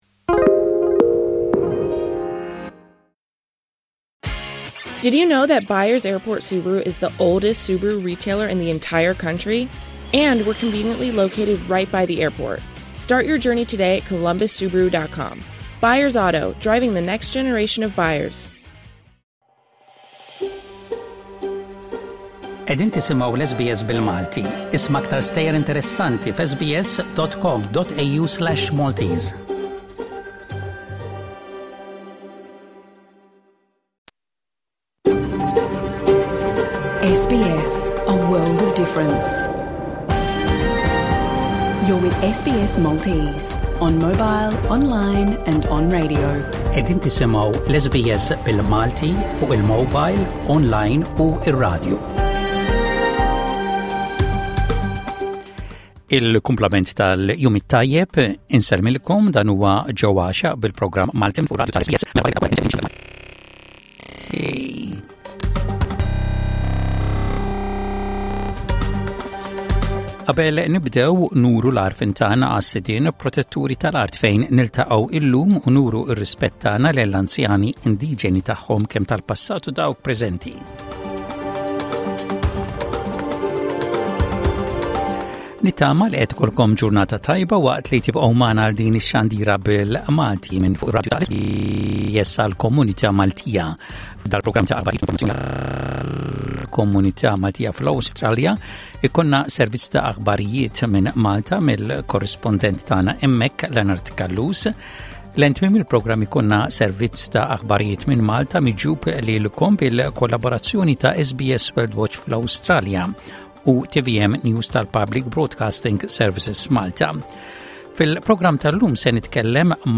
Avviżi komunitarji Share